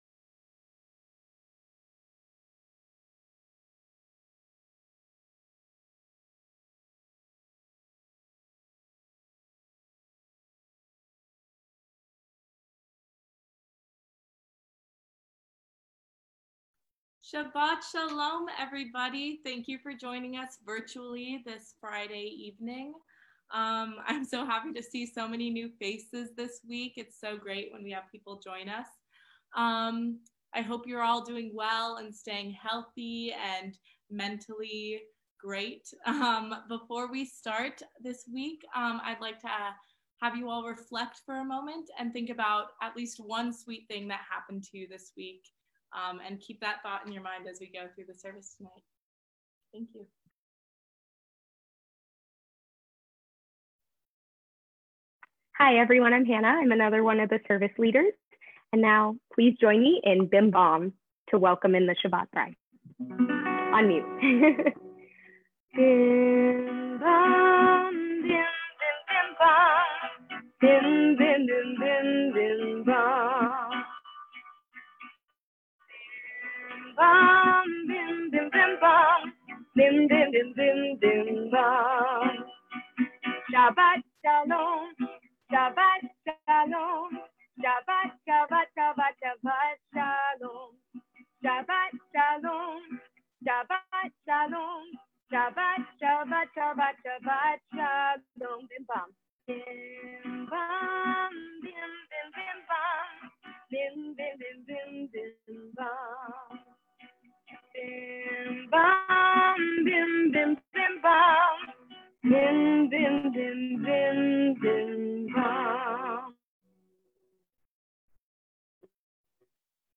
Santa Barbara Hillel May 01 Shabbat Facebook Livestream (untitled)